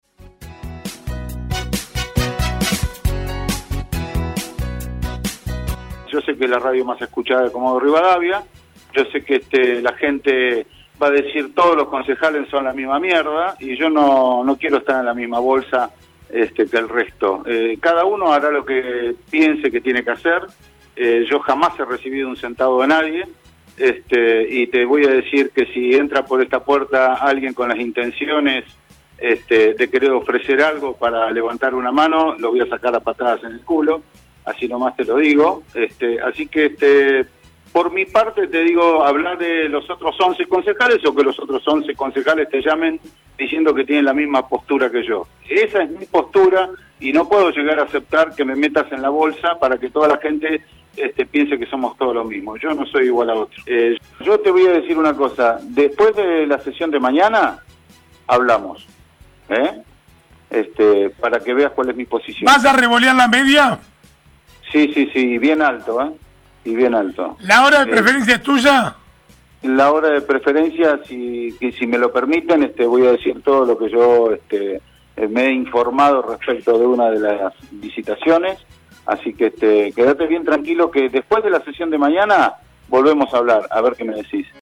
El concejal Omar Lattanzio en comunicación con el programa “La mañana de hoy” de Radiovision, manifestó que “después de la sesión del jueves voy a revolear la media”.